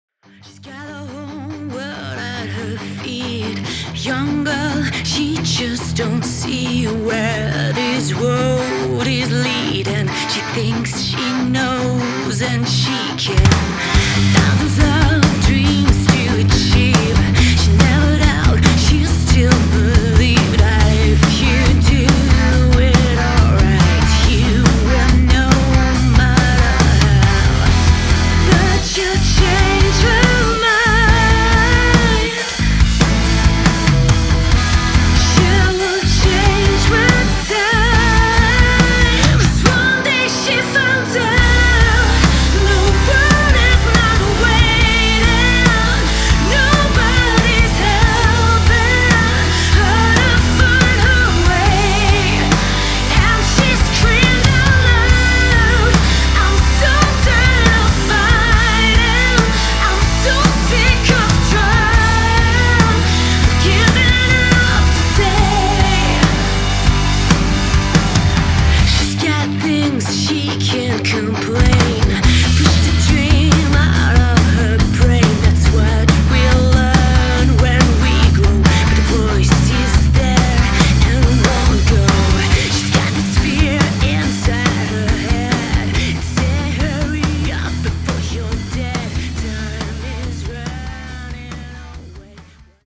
crystalline and captivating voice
Guitar
Bass
Drums